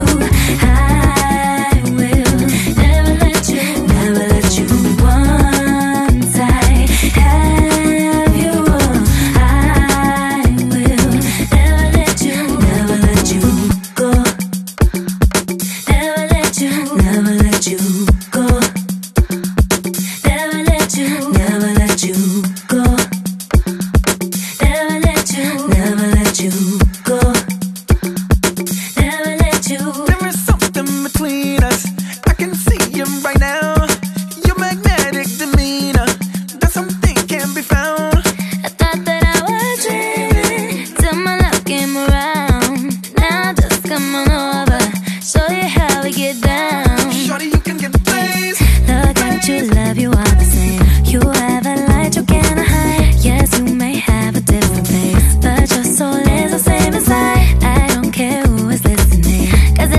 BackingVocals Mix